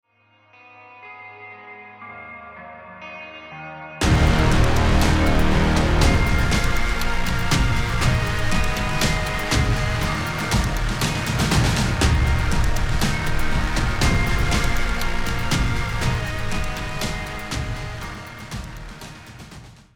音色はEPIC系でよくあるクリーンギターを選択。
曲が盛り上がるところでベースを鳴らしたい。
パターンジェネレータの[パターンスタイル]から、ベースラインを選択。
これで、ギターアルペジオとベースとリズムの完成。